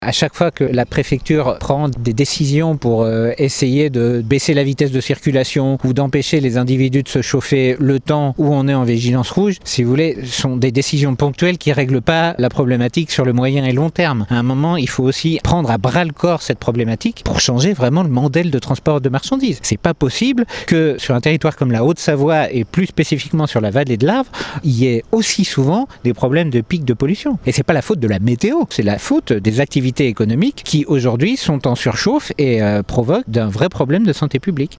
Les mesures réglementaires prises par l'Etat ne suffisent pas selon le haut-savoyard Benjamin Joyeux, conseiller régional les écologistes :